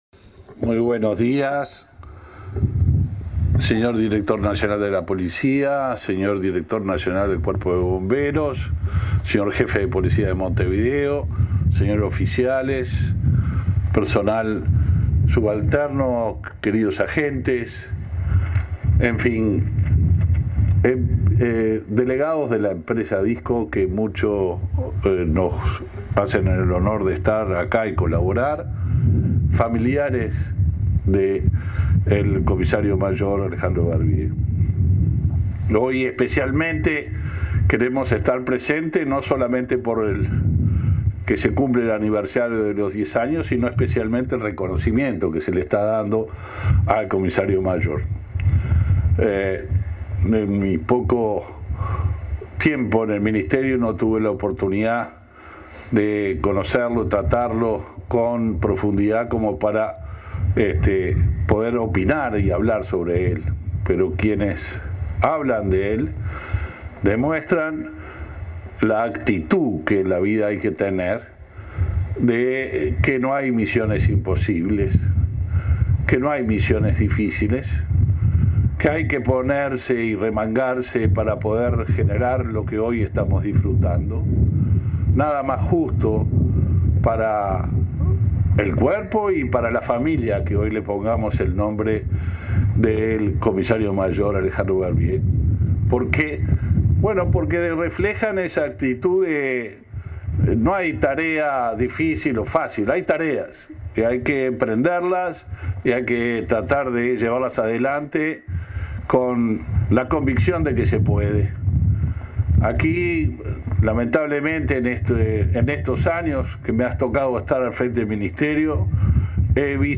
Conferencia de prensa por el aniversario del Centro de Capacitación de Bomberos
Conferencia de prensa por el aniversario del Centro de Capacitación de Bomberos 16/08/2022 Compartir Facebook X Copiar enlace WhatsApp LinkedIn El ministro del Interior, Luis Alberto Heber, participó, este 16 de agosto, en el 10.° aniversario del Centro de Capacitación de Bomberos, ubicado en Las Brujas, Canelones. Participó, además, el director nacional de Bomberos, Ricardo Riaño.